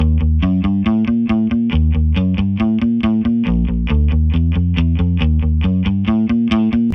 驾驭60年代风格的基调
描述：一把电贝斯吉他弹出了一个驱动性的八分音符的旋律。
Tag: 138 bpm Pop Loops Bass Guitar Loops 1.17 MB wav Key : D